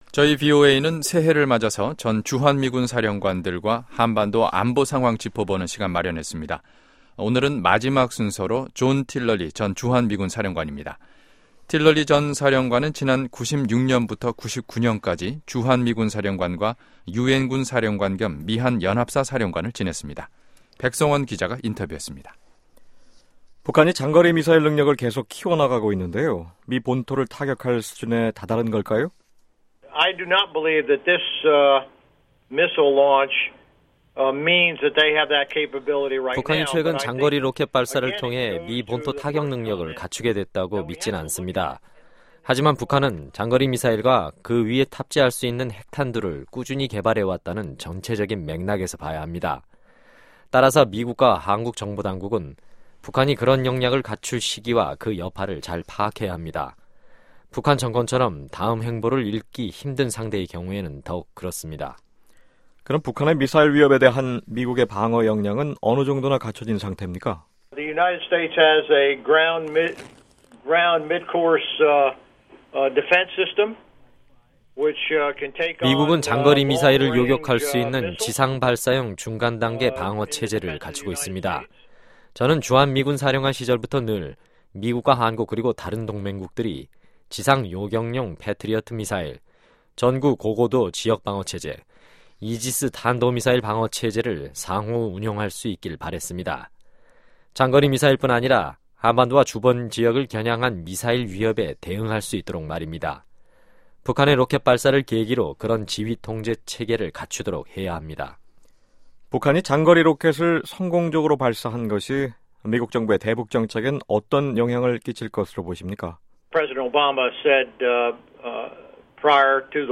저희 VOA방송은 신년을 맞아 전 주한미군사령관들로부터 한반도 안보상황을 짚어보는 시간을 마련했습니다. 오늘은 세번째 마지막 순서로 존 틸럴리 전 주한미군사령관과의 인터뷰를 전해 드립니다.